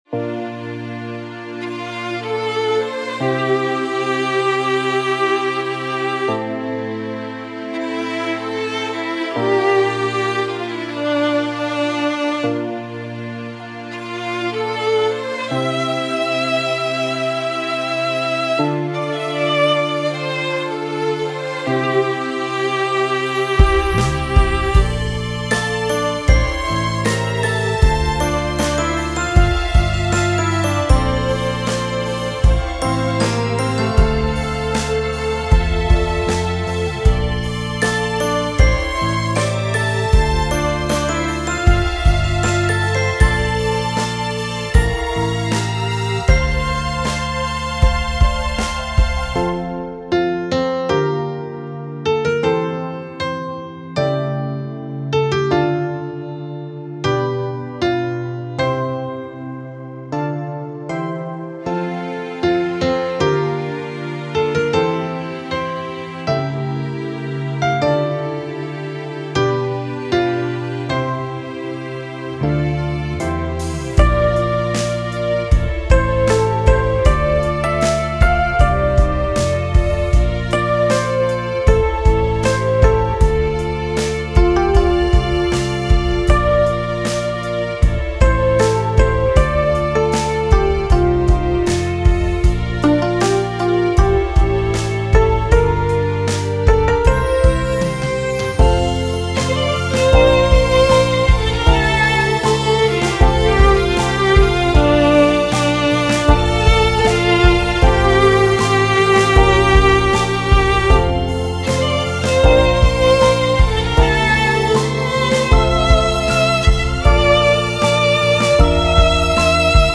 フリーBGM イベントシーン 感動的・ドラマチック